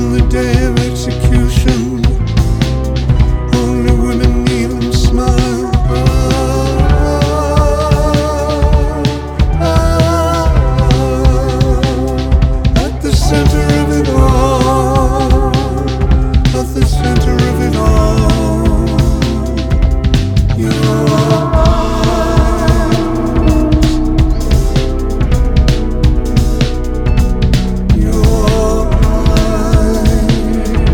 "templateExpression" => "Pop"